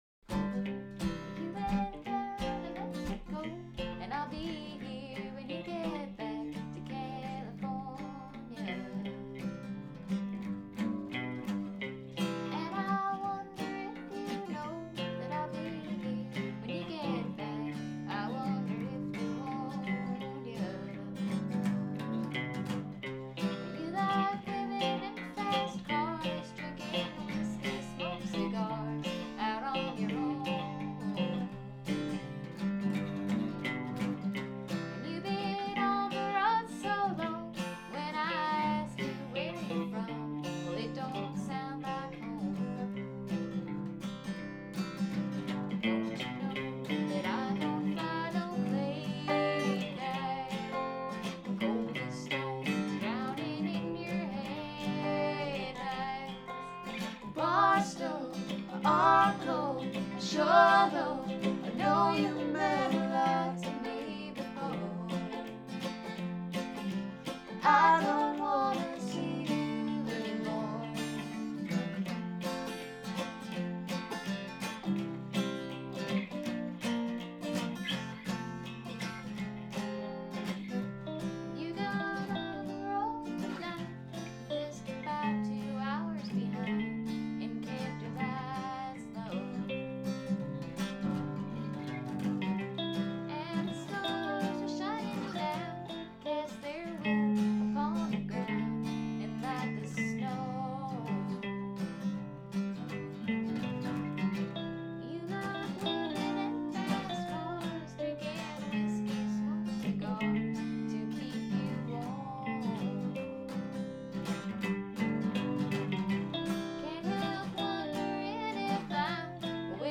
Added a recording from practice last night!